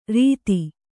♪ rīti